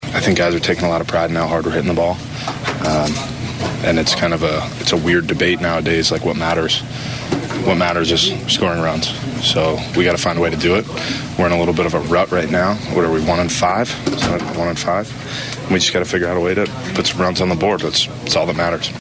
First baseman Vinny Pasquantino says they need to start scoring runs.